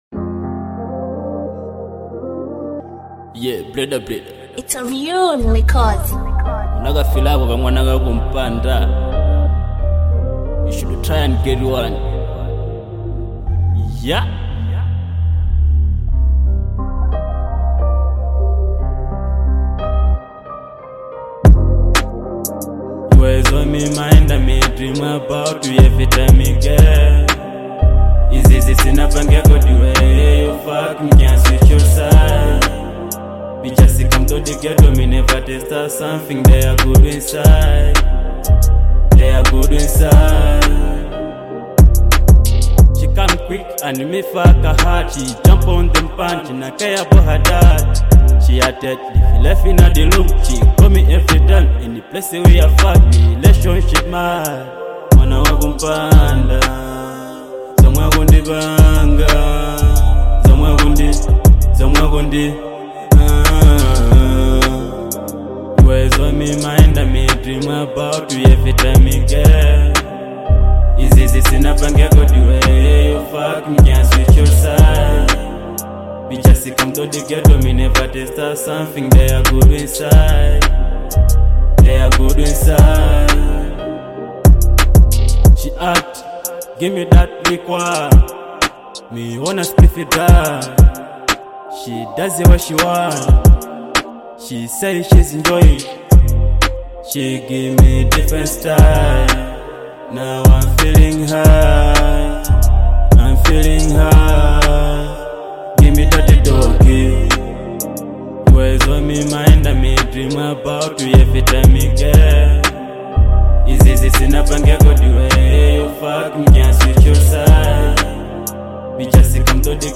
Genre : Afro Beat